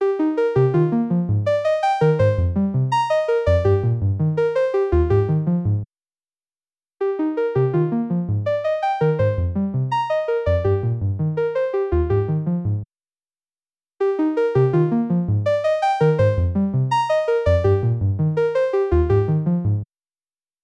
耳障りさを引き起こすことなく、トーナルな高域を緻密に追加することによる、微細な調整から劇的な変化まで、ボーカル、シンセ、その他の楽器の音楽的なリバランス
Sheen-Machine-Rebalance.wav